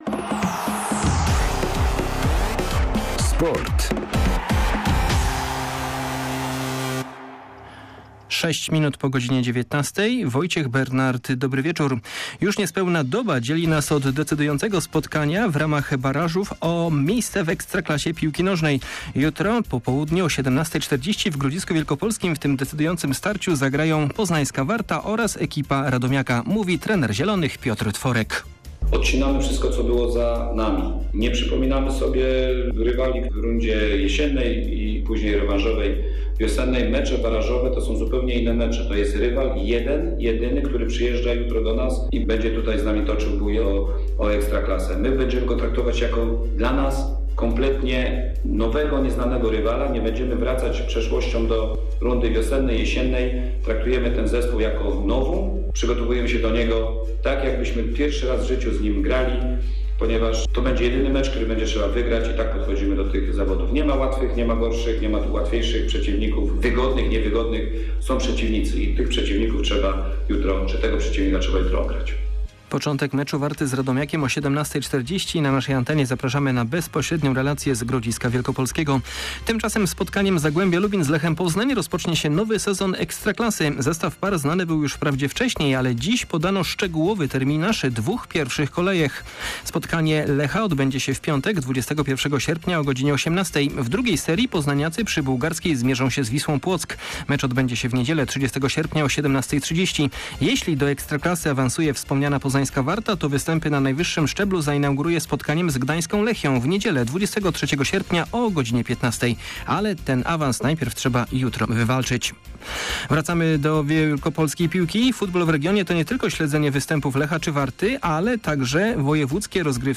30.07. SERWIS SPORTOWY GODZ. 19:05